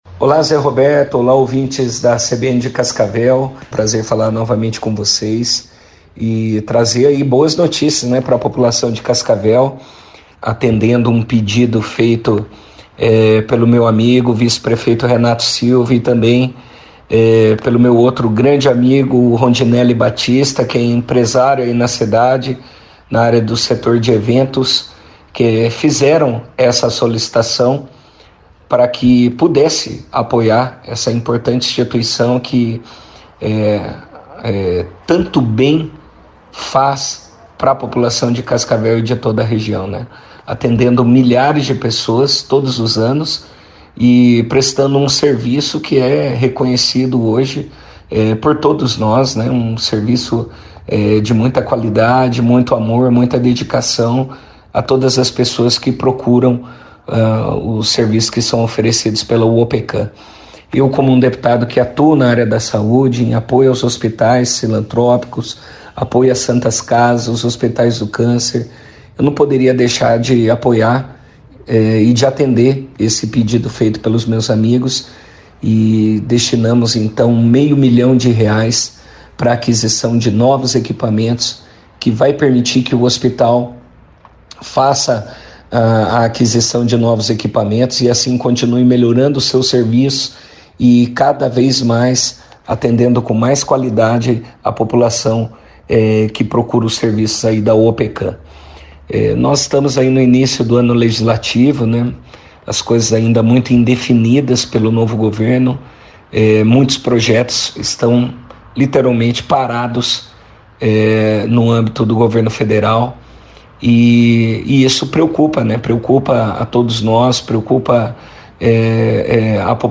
Em entrevista à CBN Cascavel nesta quinta-feira (09), o deputado federal Diego Garcia falou da reunião da bancada paranaense com o ministro dos Transportes, Renan Filho. Mas, inicialmente, Garcia destacou a importância da Uopeccan e confirmou a destinação de uma verba de R$ 500 mil ao hospital do câncer de Cascavel, acompanhe.
Player Ouça Diego Garcia, deputado federal